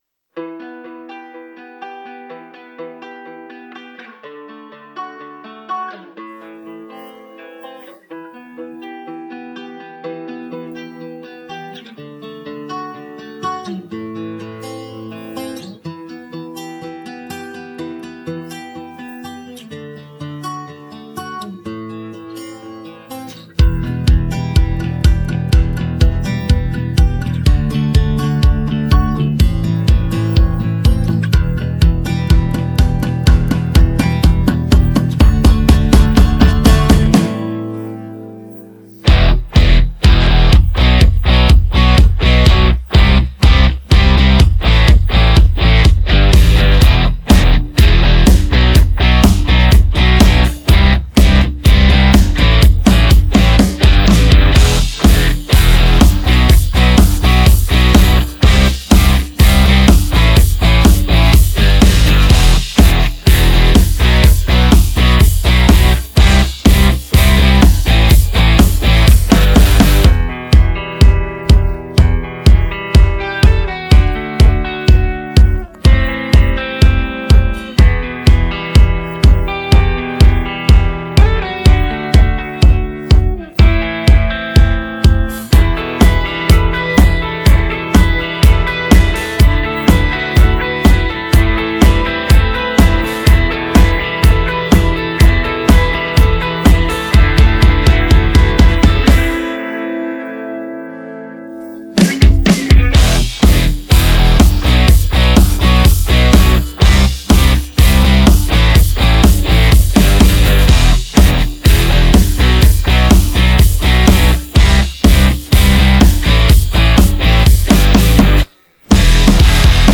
Качественный минус